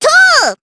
Miruru-Vox_Attack2_jp.wav